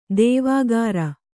♪ dēvāgāra